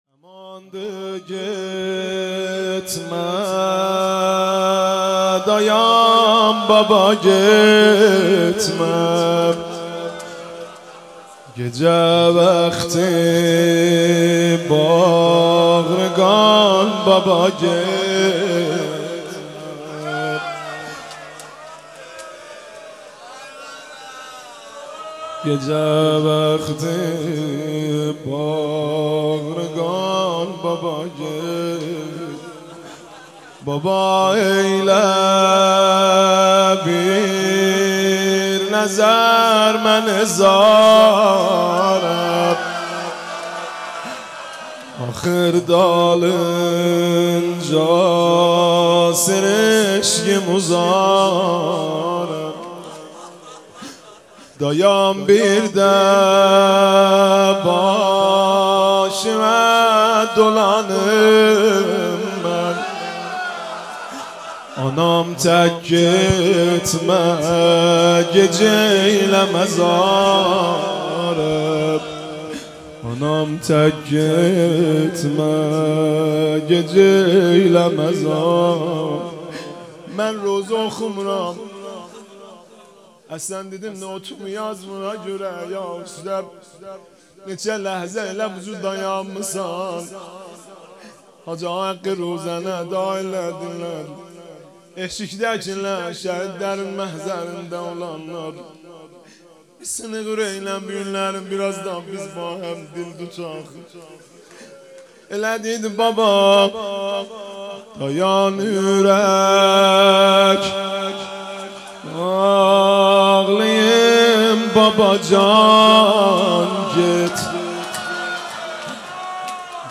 دانلود مناجات خوانی و مداحی زیبا و دلنشین از حاج مهدی رسولی در شب بیست و یکم ماه رمضان ویژه شب قدر